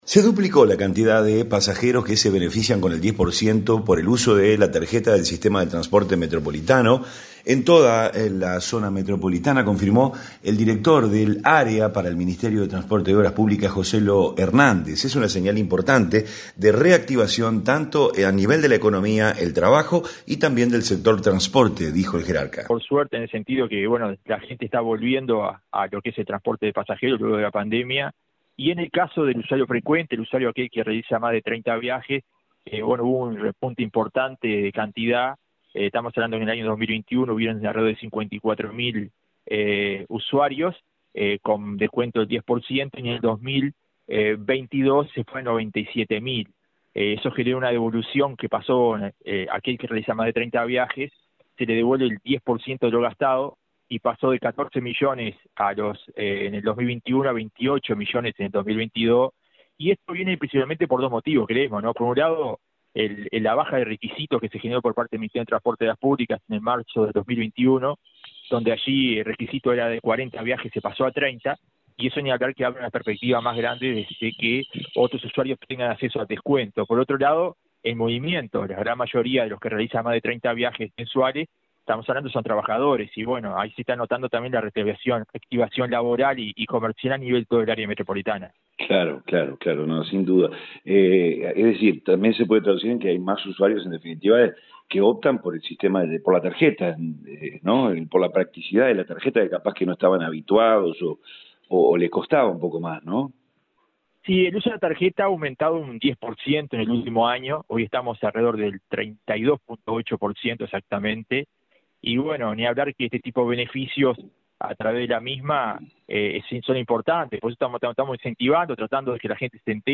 REPORTE-TRANSPORTE.mp3